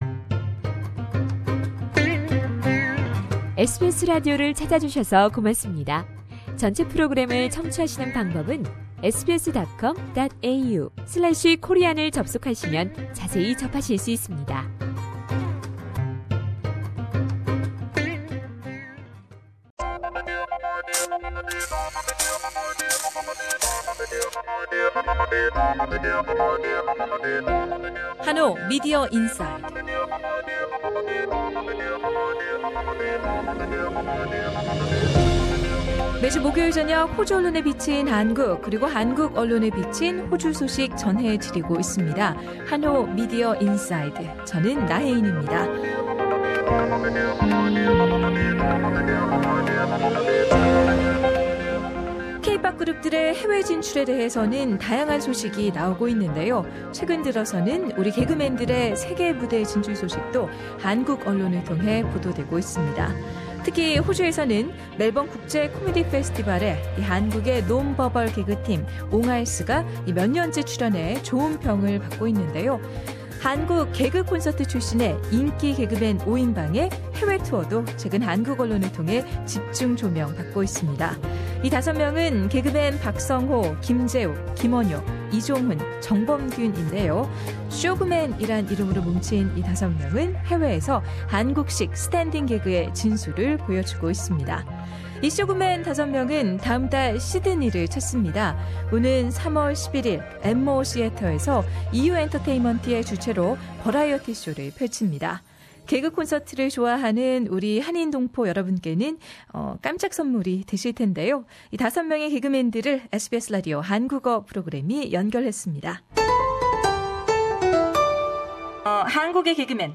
SBS Radio Korean Program has a telephone interview with five funniest men in Korea, Sung-ho Park, Jae-wook Kim, Won-hyo Kim, Jong-hoon Lee and Bum-kyun Jeong.
The comedians said their earlier gigs in USA were very successful and they promise to provide big laughs and breezy good time to Aussie audiences. You can listen to the full interview with the five comedians by clicking the play button above.